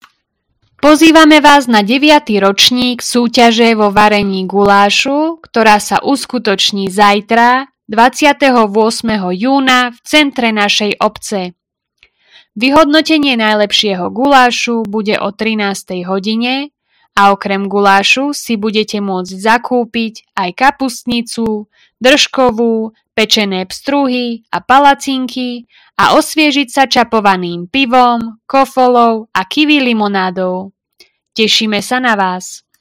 Hlásenie obecného rozhlasu – Čerenianska gulášovačka 2025